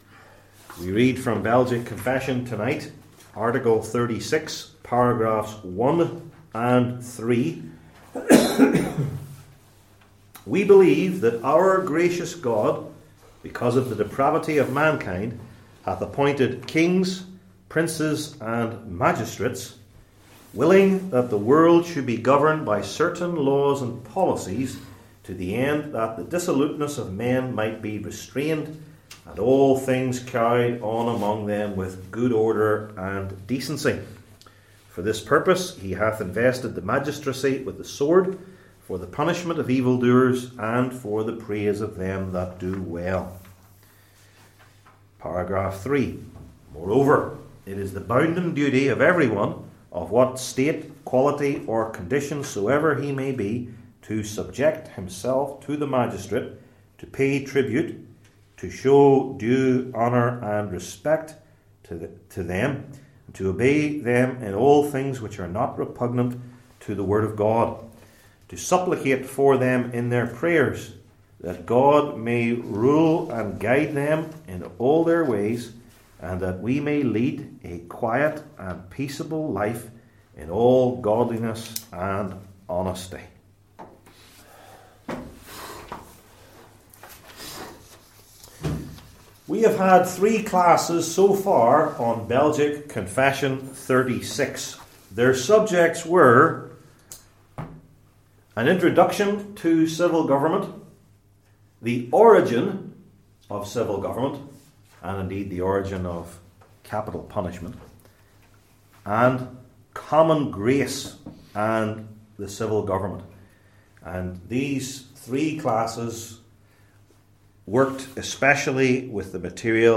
Passage: Romans 13:1-10 Service Type: Belgic Confession Classes